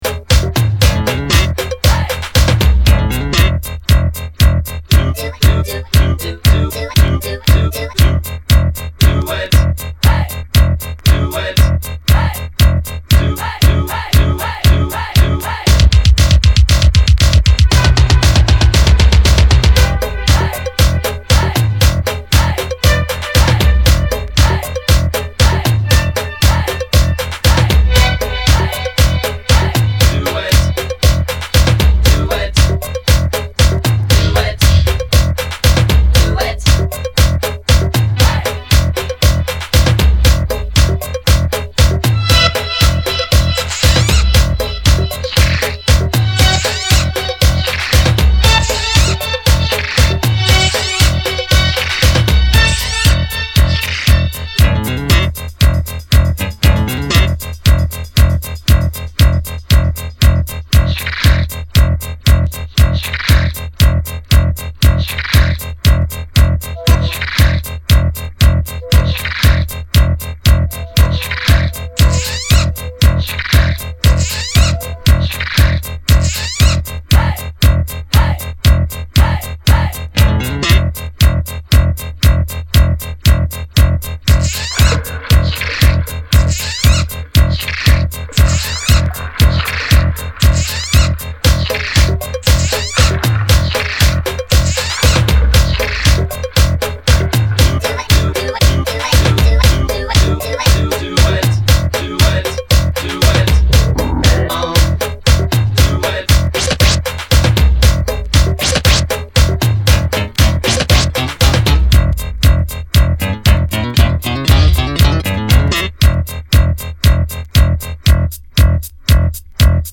the famous & muscular disco anthem from we all grew up with.
Disco House